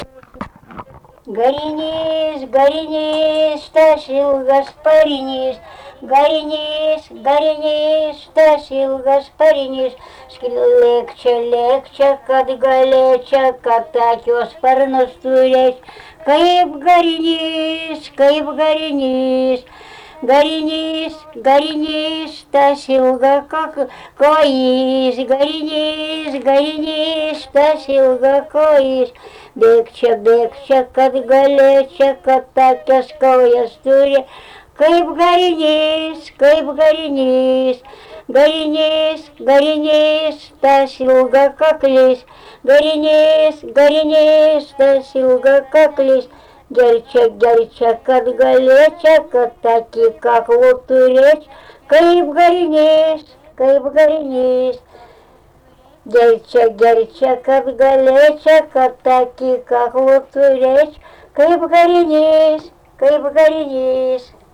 Dalykas, tema daina
Erdvinė aprėptis Jūžintai
Atlikimo pubūdis vokalinis